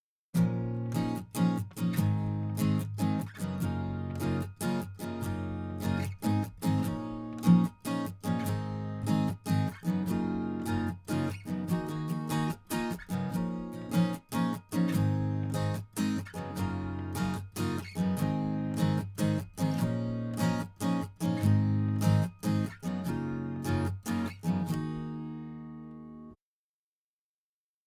No UV EQ applied to audio
Acoustic 2 Dry.wav